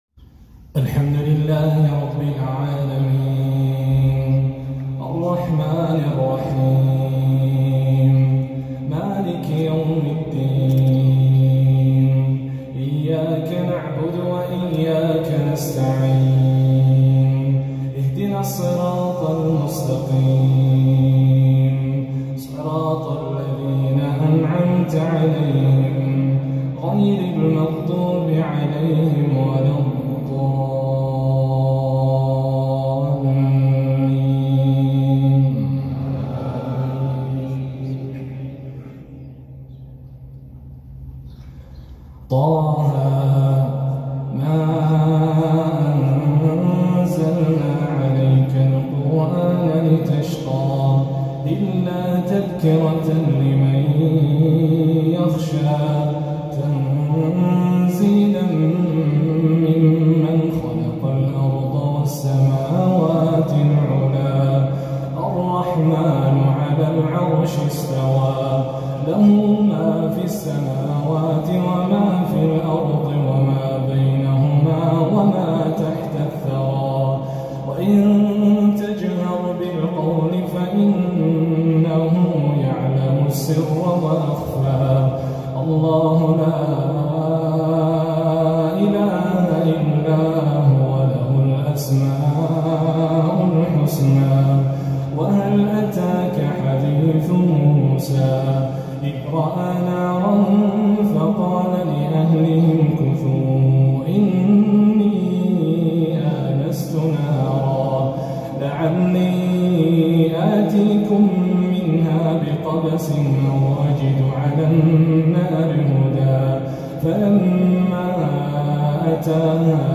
صلاة الكسوف كاملة
تلاوة مرئية وصوتية إبداعية
سورة طه بجودة عالية وغالية